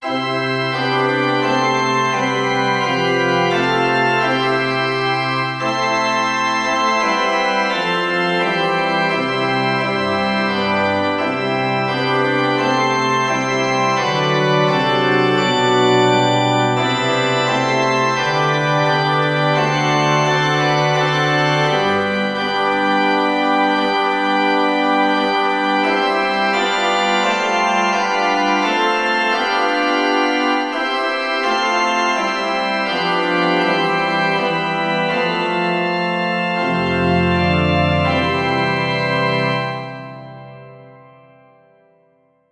The choir sang“On the Resurrection Morning.” and Now The Labourer’s Task Is O’er. You can listen to the tune of this lovely old Victorian hymn by clicking the media player below.
Hymn